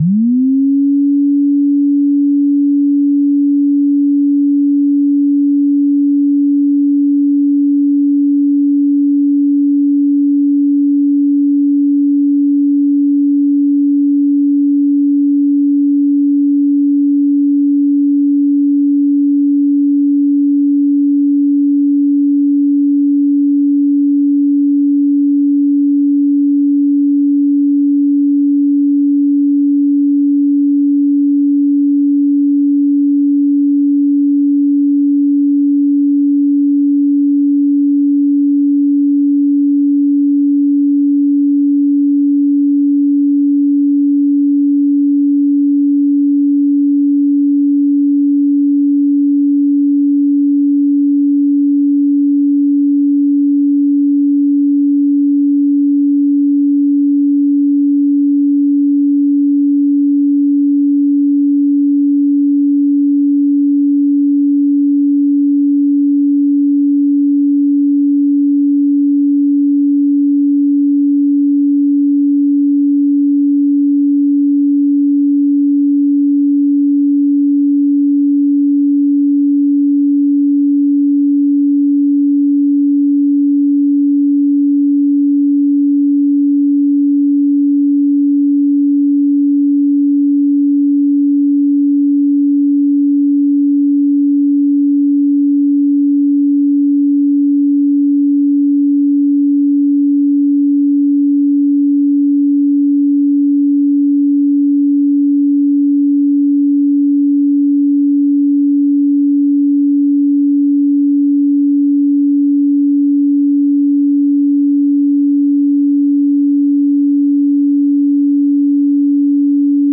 285 Hz Tone Sound Solfeggio Frequency
Solfeggio Frequencies